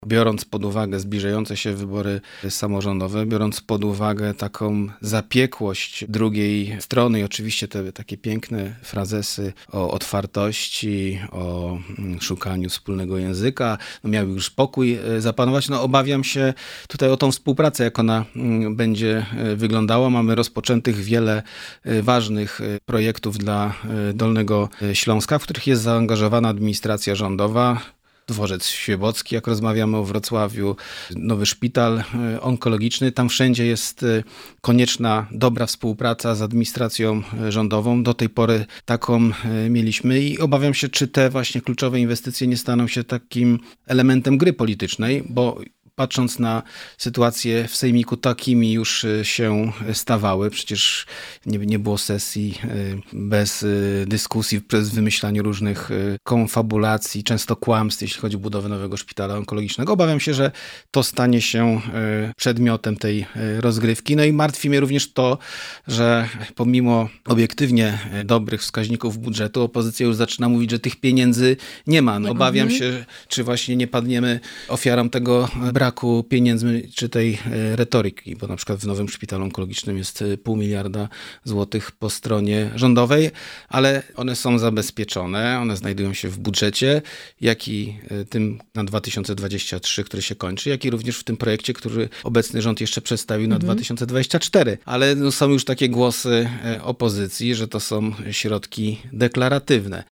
-Mam obawy, by po wyborach nasze ważne projekty nie stały się elementem gry politycznej, mówi Marcin Krzyżanowski – wicemarszałek województwa dolnośląskiego.